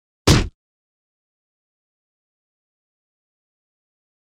赤手空拳击中肉体14-YS070524.mp3
通用动作/01人物/03武术动作类/空拳打斗/赤手空拳击中肉体14-YS070524.mp3
• 声道 立體聲 (2ch)